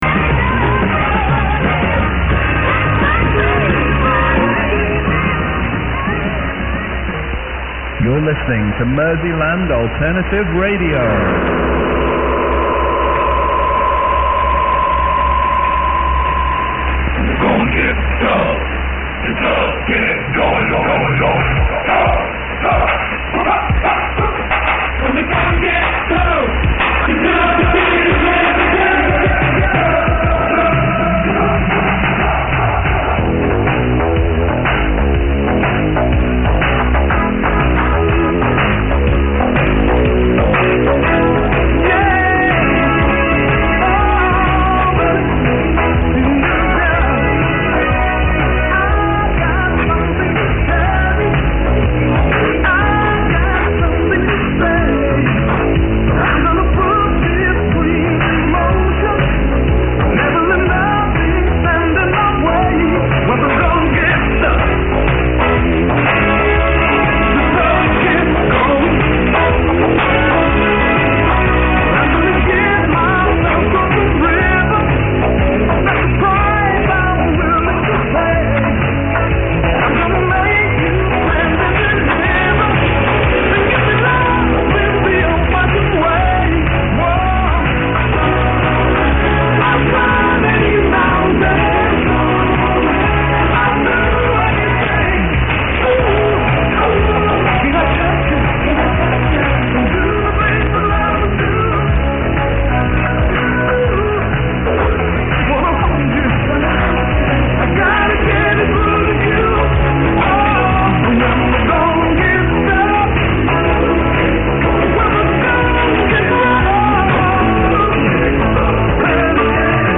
Signal was very loud and clear, and not unlike to the YOU-FM broadcast in strength.
Below I have given a short aircheck, including parts of the music when the signal was booming. Unfortunately when the chatter at the end was being heard, a wandering carrier which I assume may be Dutch, had come on the frequency.